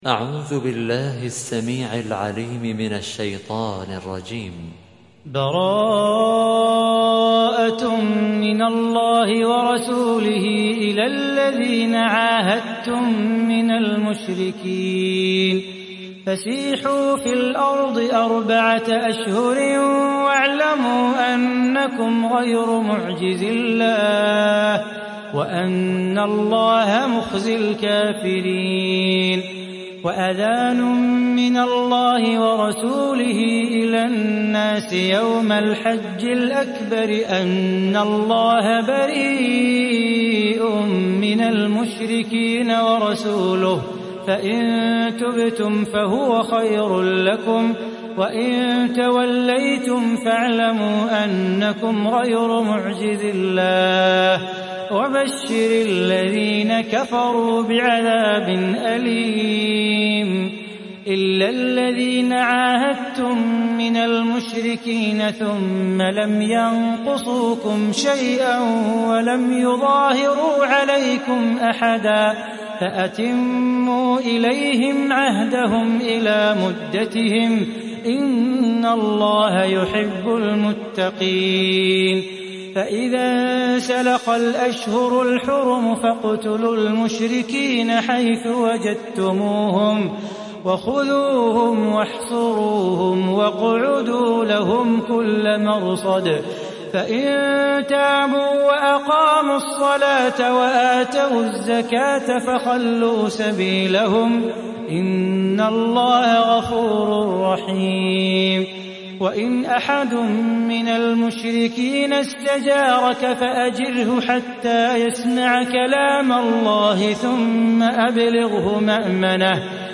Sourate At Tawbah mp3 Télécharger Salah Bukhatir (Riwayat Hafs)
Sourate At Tawbah Télécharger mp3 Salah Bukhatir Riwayat Hafs an Assim, Téléchargez le Coran et écoutez les liens directs complets mp3